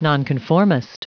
Prononciation du mot nonconformist en anglais (fichier audio)
Prononciation du mot : nonconformist